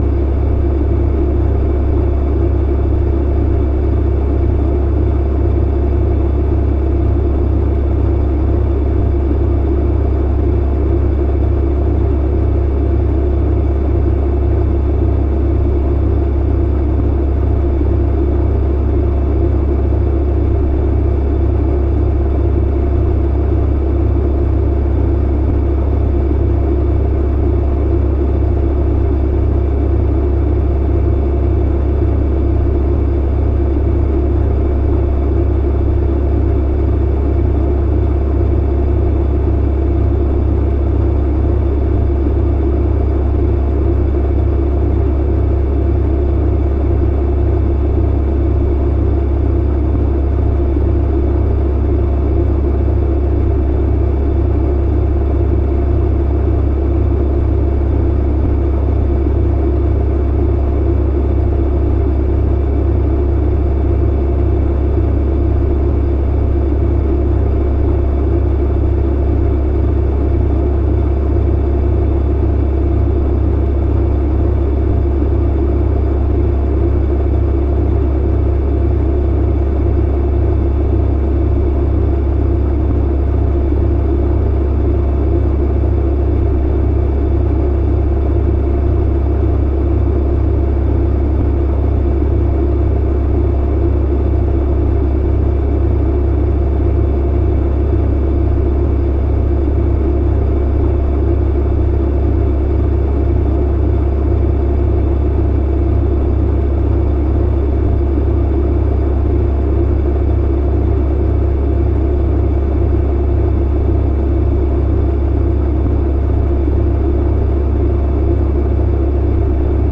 An audio that I’ve found reproduces a dryer sound.
04-Dryer-60min.mp3